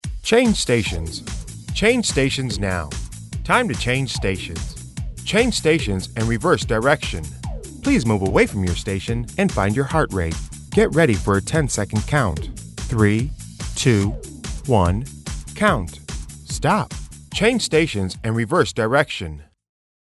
All our Cue CDs are studio recorded and work great on all 30 minute style fitness circuits.
Double Take Cue: A basic Male or Female prompt to change stations 3 times and on the 4th change station prompt it adds to reverse direction.
The "previews" have music background for reference.
MALE CUES
CueCD-DblTk-Male.mp3